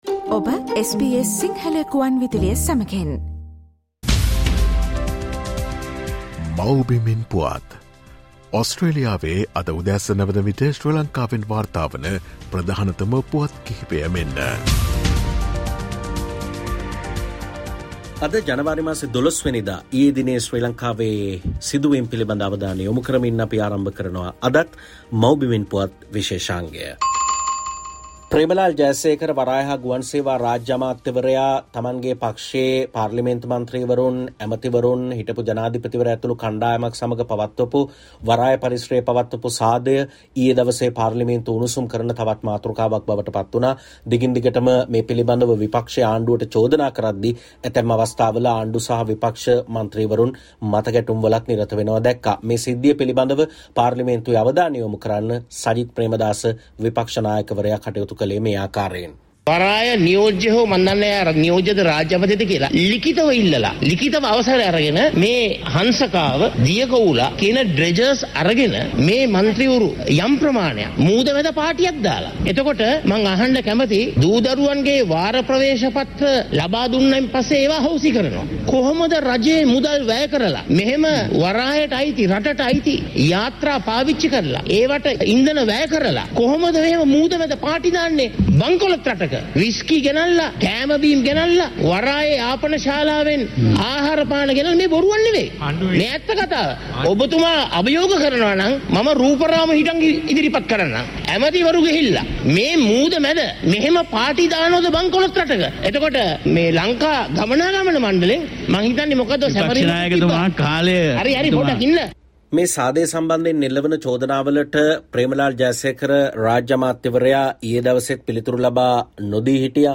SBS Sinhala featuring the latest news reported from Sri Lanka